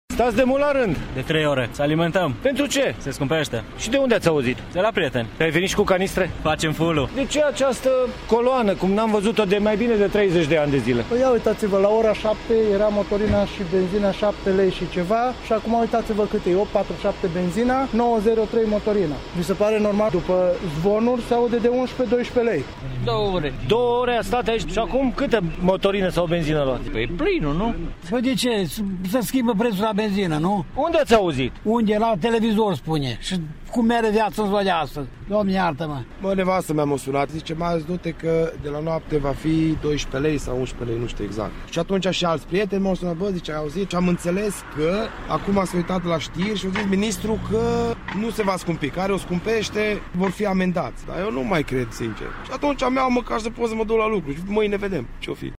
vox-benzna.mp3